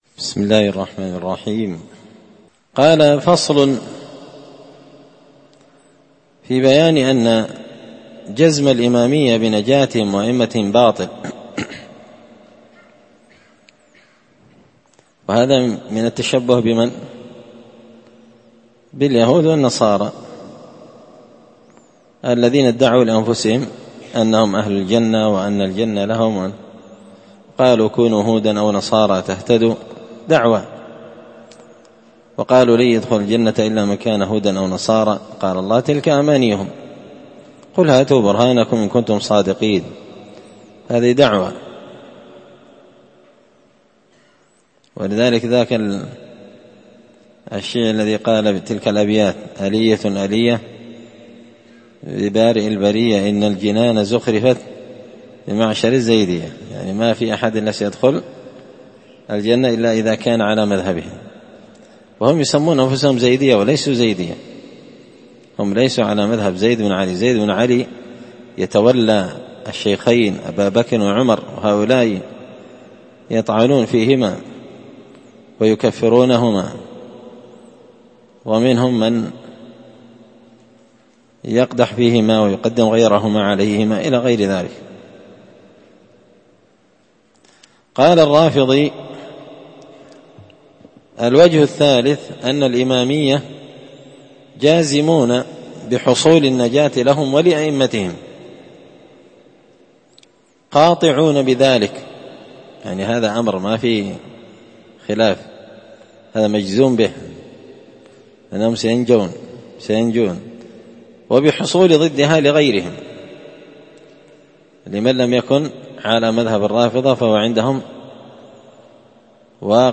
الخميس 8 رمضان 1444 هــــ | الدروس، دروس الردود، مختصر منهاج السنة النبوية لشيخ الإسلام ابن تيمية | شارك بتعليقك | 6 المشاهدات